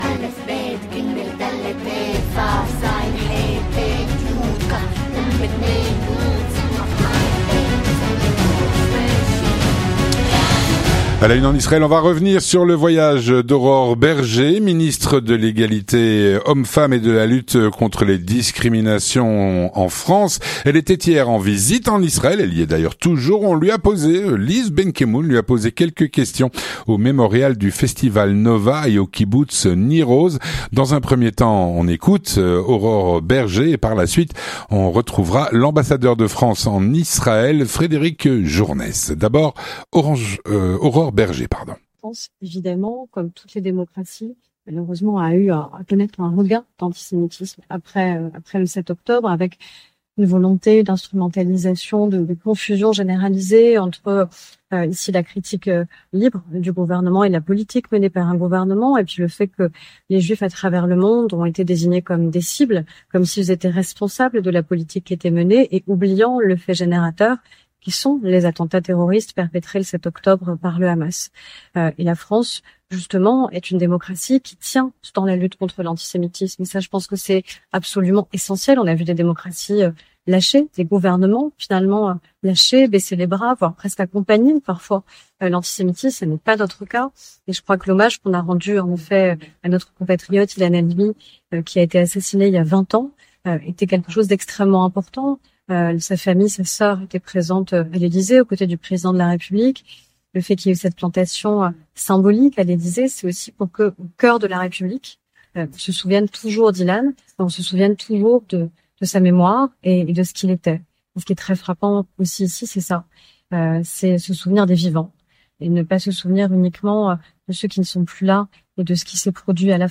Aurore Bergé Ministre de l’égalité hommes-femmes et de la lutte contre les discriminations était hier en visite en Israël on lui a posé quelques questions au mémorial du festival Nova et au kibboutz Nir Oz.
Frédéric Journes, ambassadeur de France en Israël donnait, quant à lui, hier, une réception en l’honneur de la ministre française Aurore Bergé. Il y a tenu un discours très franc sur les rapports de la France et d’Israël.